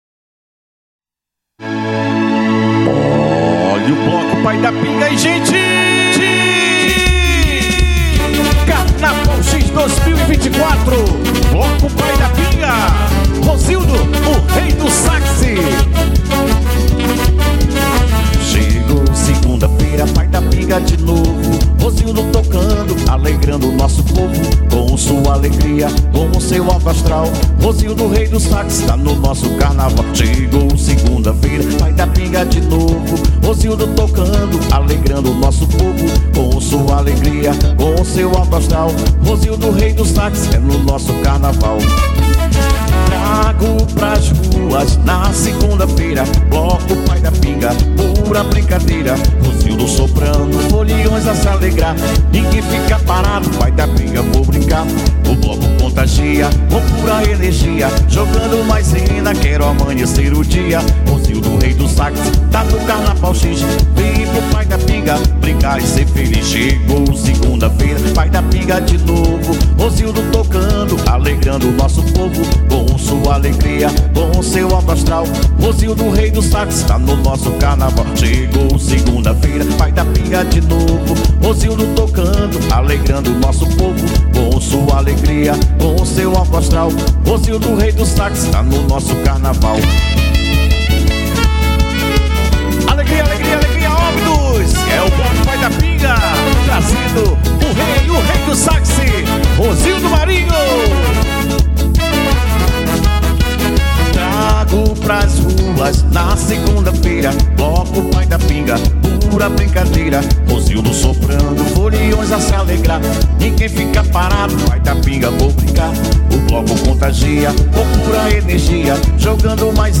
teclado
guitarra